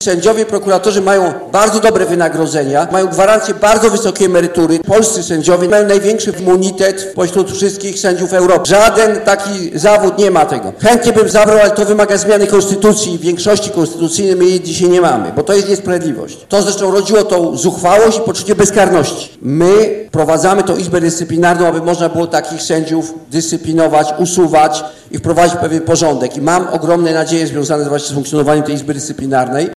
O konieczności: reformy wymiaru sprawiedliwości, eliminowania niegodnych sędziów i prokuratorów z zawodu i zrównania wszystkich wobec prawa mówił w Biłgoraju minister sprawiedliwości Zbigniew Ziobro.